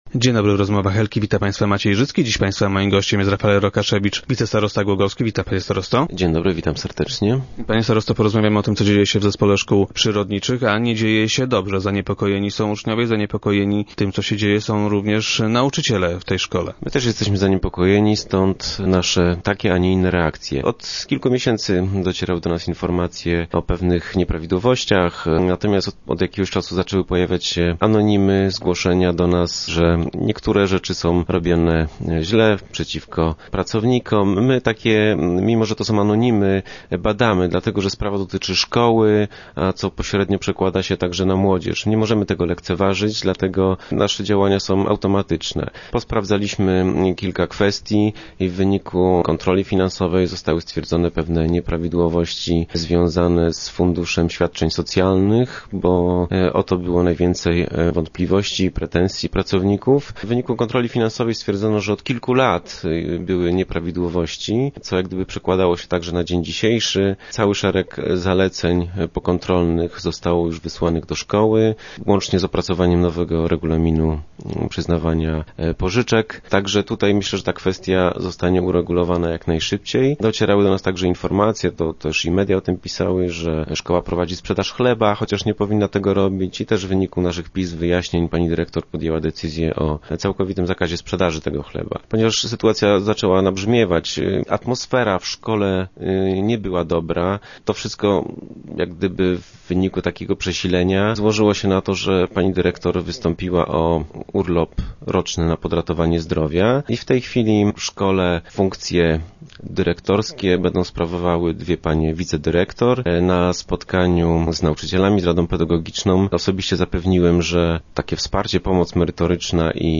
Uczniowie i pracowniczy przyrodniczej boją się o przyszłość szkoły. - Mogą być spokojni - uspokaja wicestarosta Rafael Rokaszewicz, gość dzisiejszych Rozmów Elki.